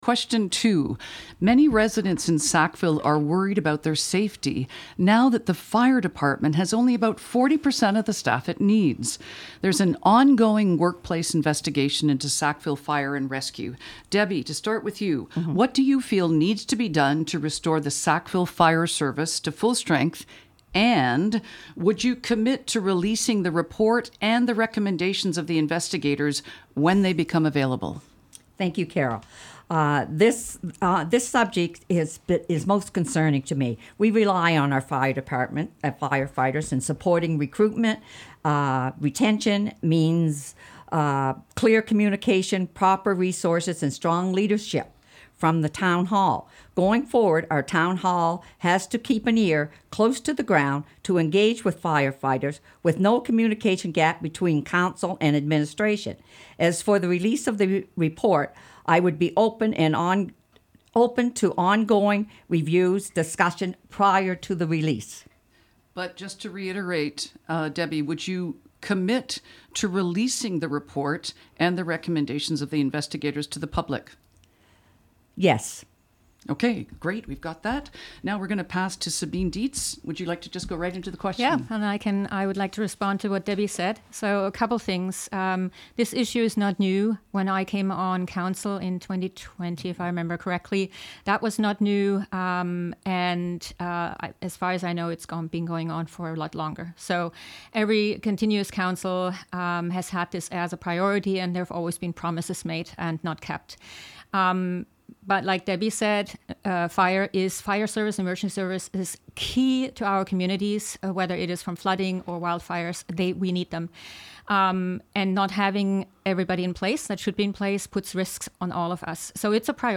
Tantramar Mayoralty Candidates Radio Forum, Friday April 17, 2026 at 2 p.m. at CHMA 106.9 FM.